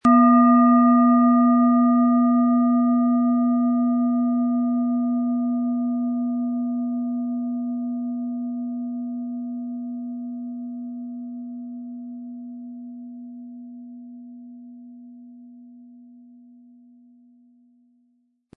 Planetenton 1
Ihre Lilith-Klangschale wird mit dem beiliegenden Schlägel schön erklingen.
SchalenformBihar
MaterialBronze